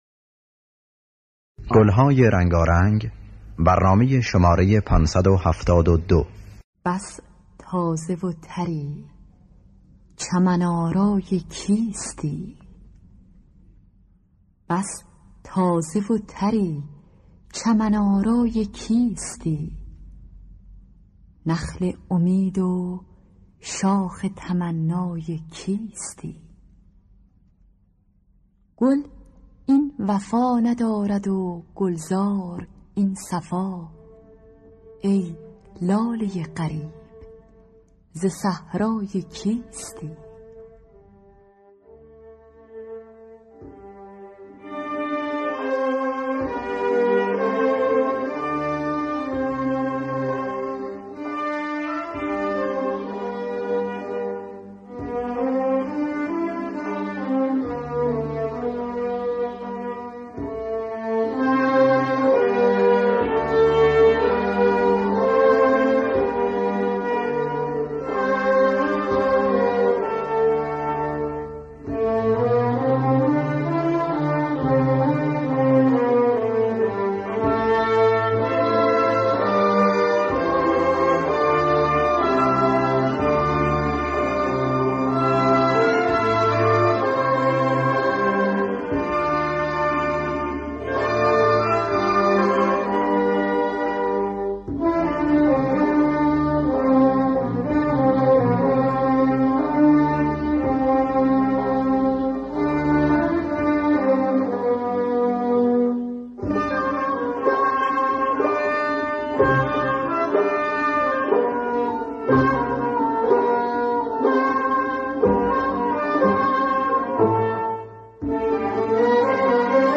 در دستگاه ابوعطا